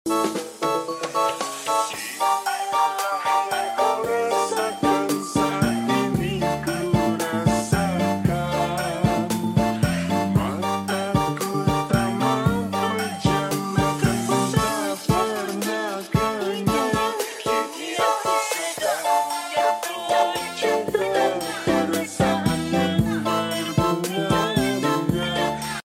funkot